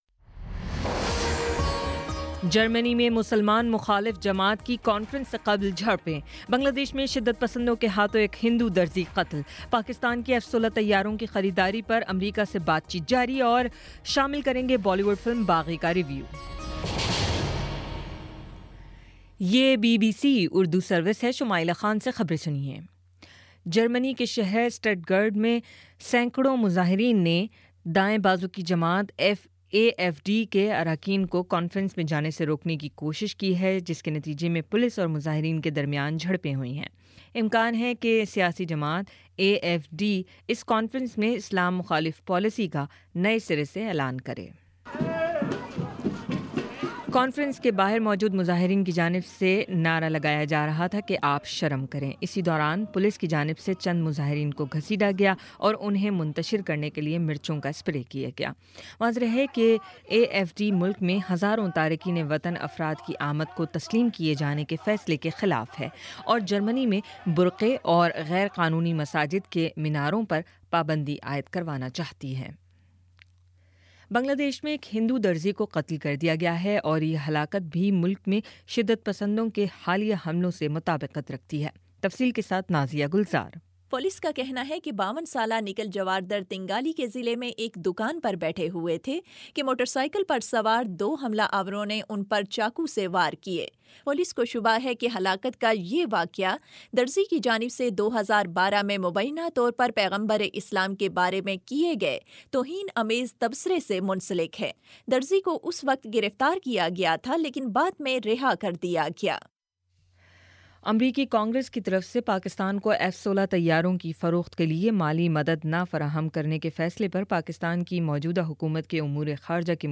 اپریل 30 : شام پانچ بجے کا نیوز بُلیٹن